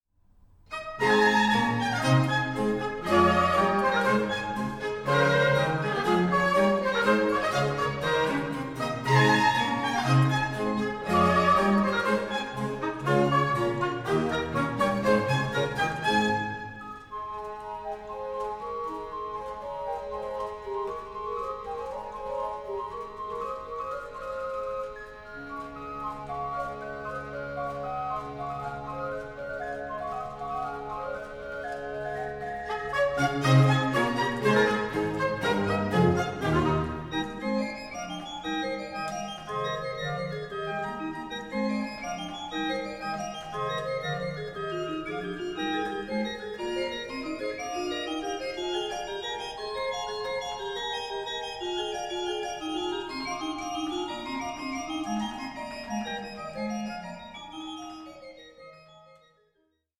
Organ Concerto No.13 in F
18) Allegro (from Violin Sonata Op.1 No.14) 1: 25